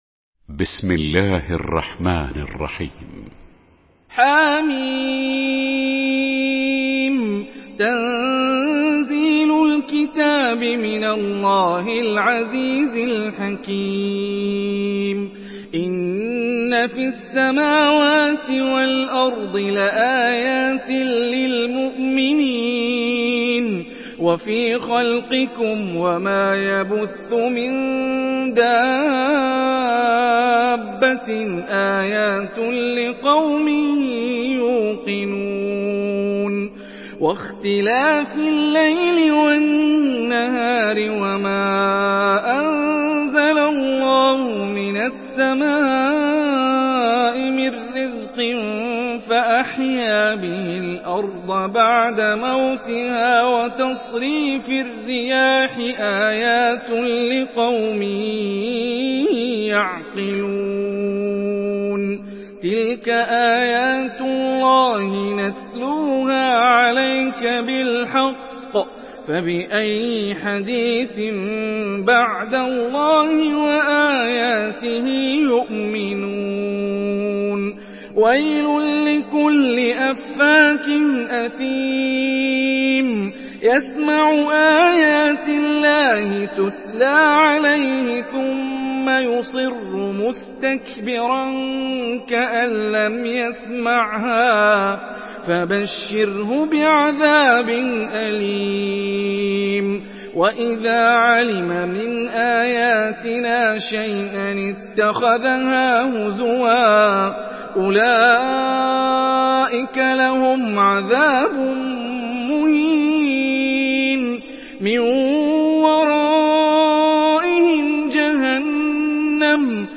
Surah আল-জাসিয়া MP3 by Hani Rifai in Hafs An Asim narration.
Murattal Hafs An Asim